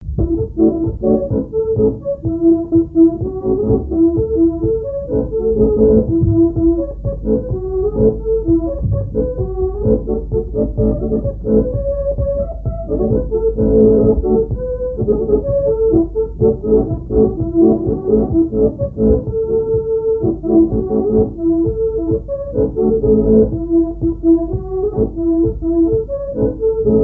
danse : polka
Répertoire à l'accordéon diatonique
Pièce musicale inédite